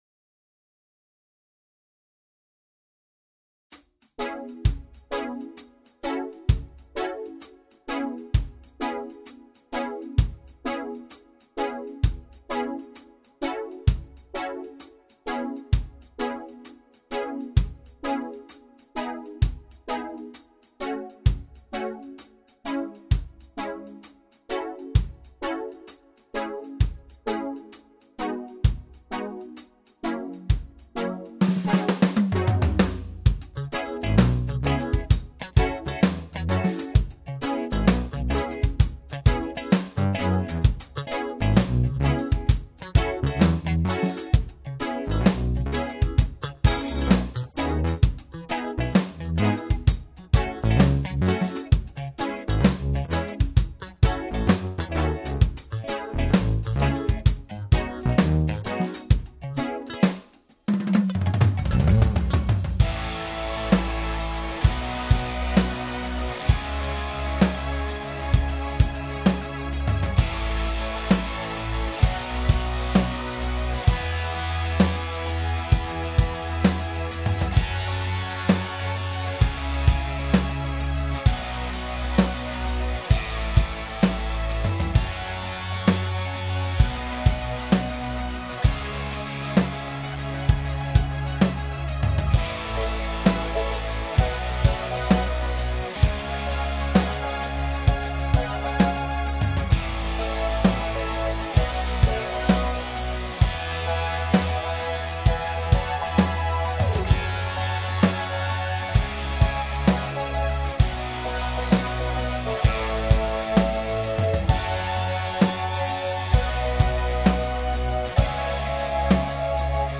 והנה עיבוד לקאבר שלא ידוע (בשלב הזה) מתי הולך לצאת…